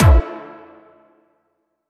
Big Synth Hit OS.wav